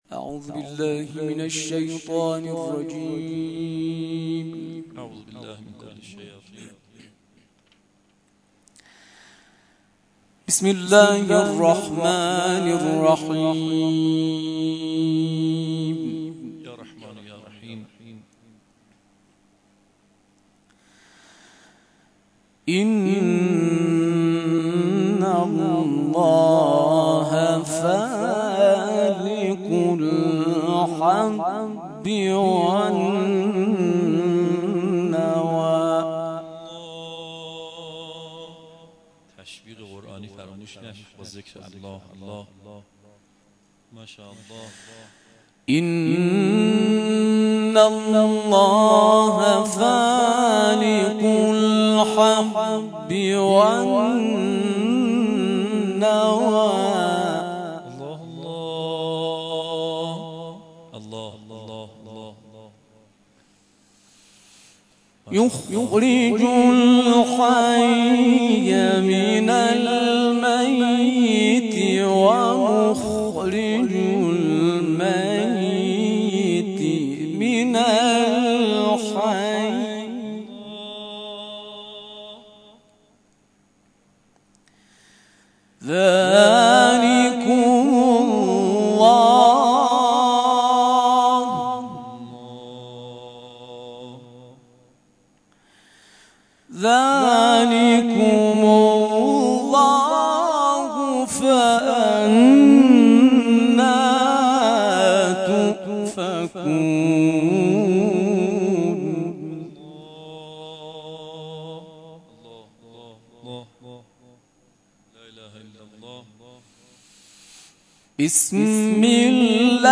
قاریان نفحات در این کرسی‌ها رایگان تلاوت می‌کنند.
یکصد و پنجمین کرسی تلاوت نفحات‌القرآن شب گذشته در مسجد جامع المهدی دهکده المپیک تهران